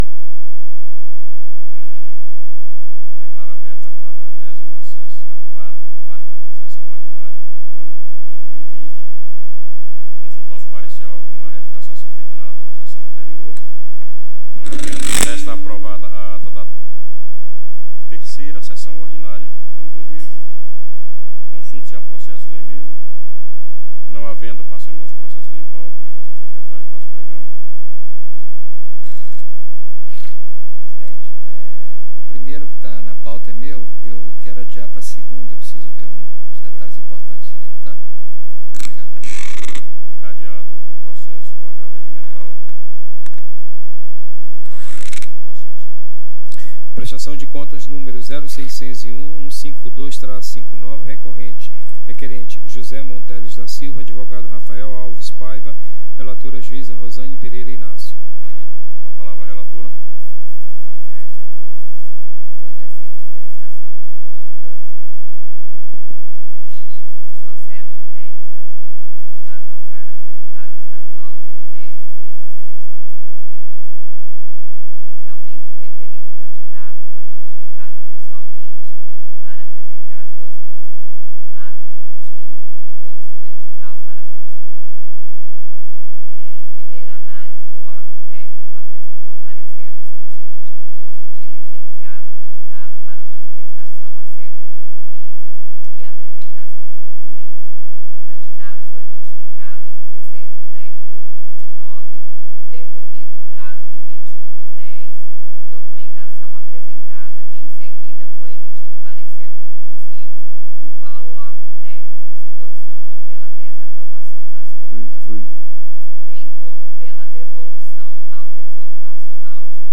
Áudio da 4ª SESSÃO ORDINÁRIA, DE 24/01/2020.